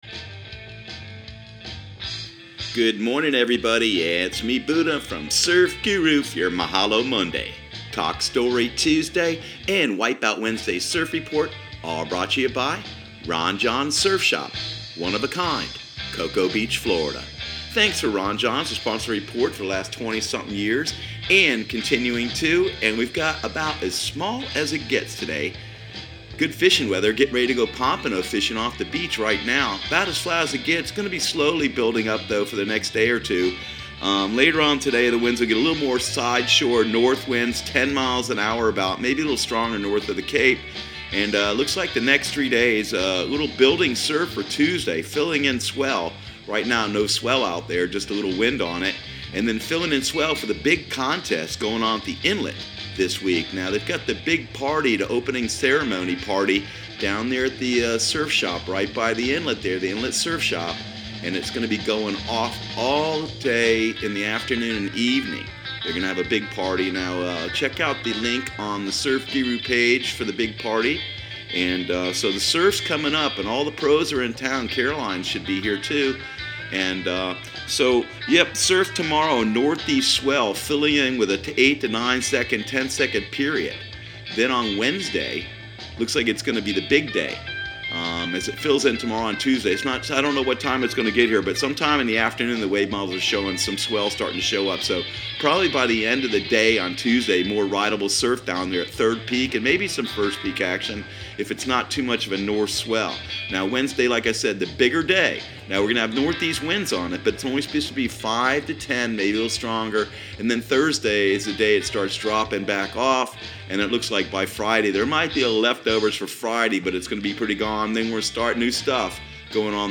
Surf Guru Surf Report and Forecast 01/14/2019 Audio surf report and surf forecast on January 14 for Central Florida and the Southeast.